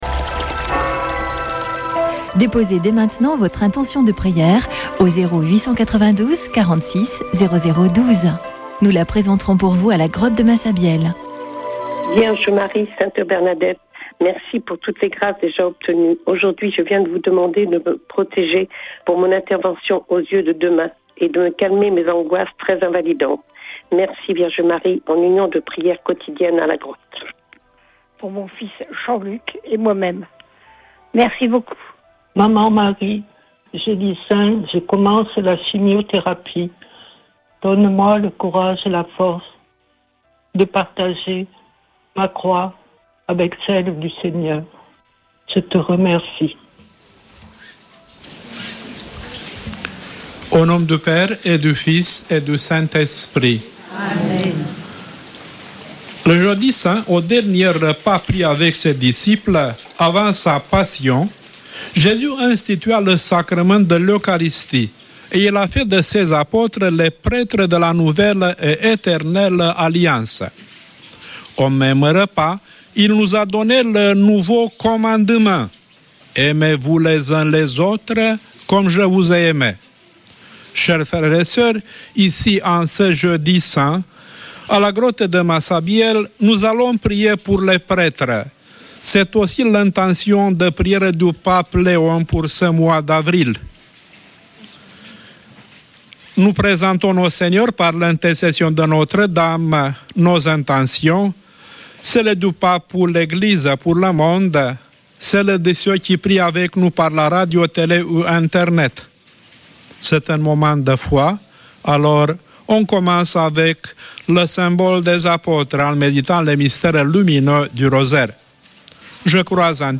Chapelet de Lourdes du 02 avr.
Une émission présentée par Chapelains de Lourdes